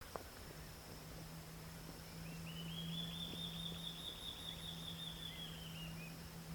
Large-tailed Antshrike (Mackenziaena leachii)
Class: Aves
Location or protected area: Parque Nacional Iguazú
Condition: Wild
Certainty: Recorded vocal